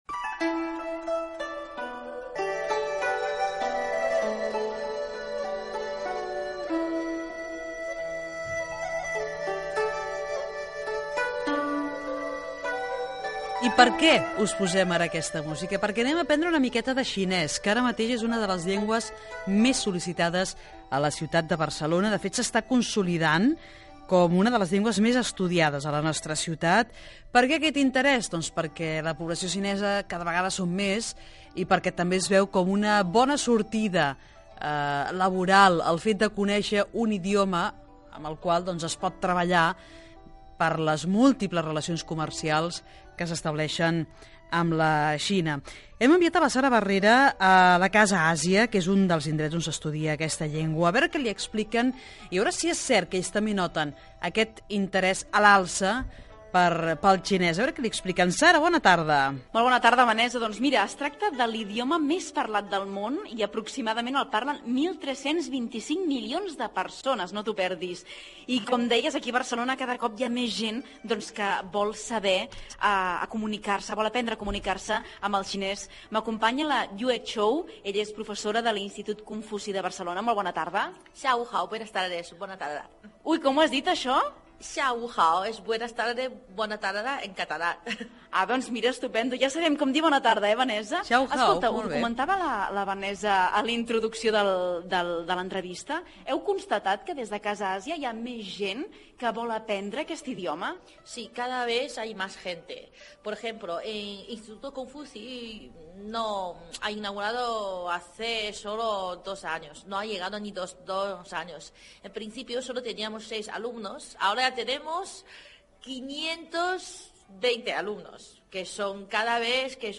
Entrevista sobre els cursos de la llengua xinesamandarín a Casa Àsia fetes per l'institut Confuci de Barcelona